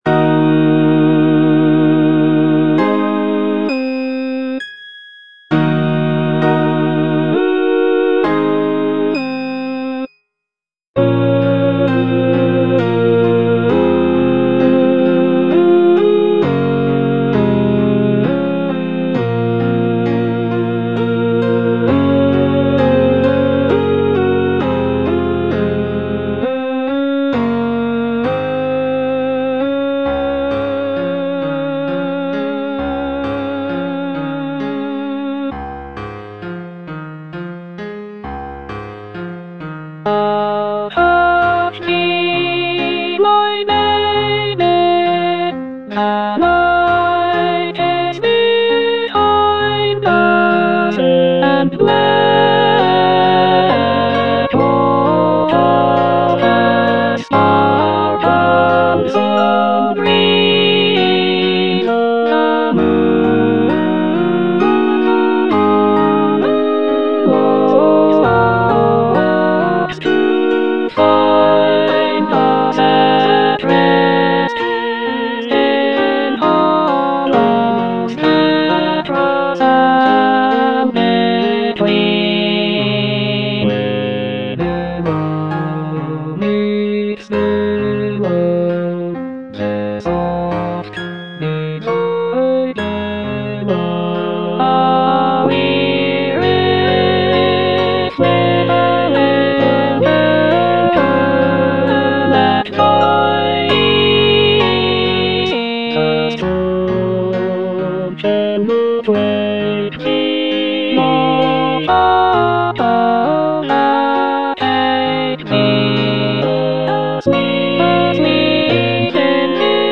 Alto (Emphasised voice and other voices)
choral work